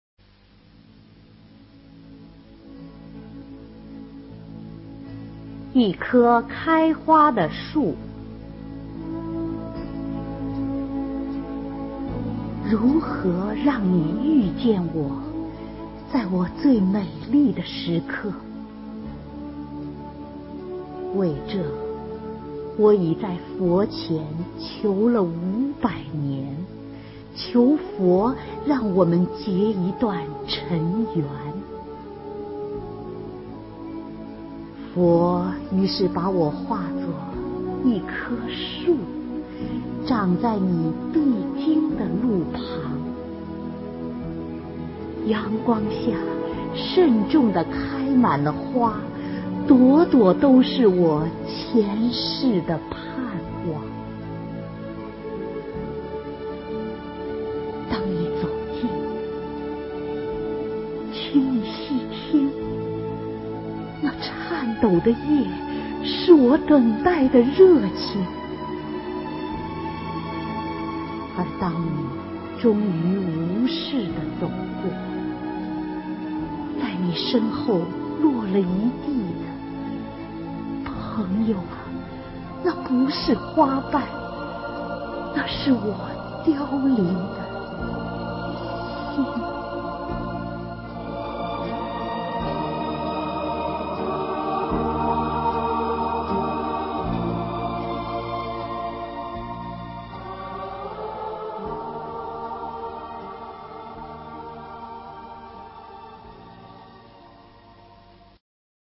首页 视听 经典朗诵欣赏 席慕容：委婉、含蓄、文雅